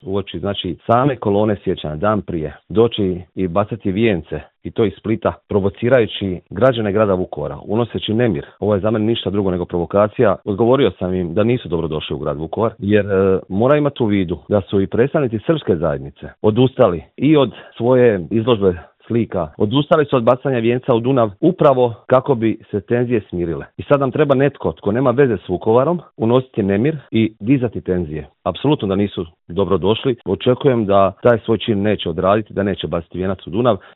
Vukovarski gradonačelnik Marijan Pavliček u Intervjuu Media servisa kaže da je grad već danima pun hodočasnika: